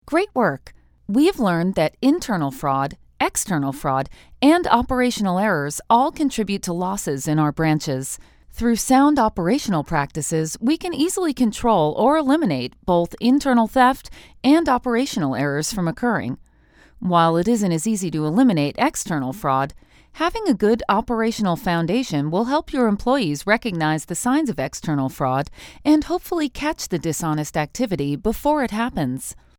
Female American voice actor for commercials, promos, narration, video games animation web and new media. Warm, Real, Conversational, Smart, Authoritative. Believable, Playful, Sexy, Hip, Stylish, Attractive, Sassy
Sprechprobe: eLearning (Muttersprache):
I specialize in today's popular "non-announcer" announce - authority and confidence, yet friendly and inviting. My voice has warmth and color, depth and personality.